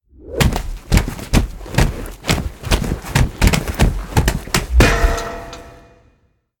anonStairFall.ogg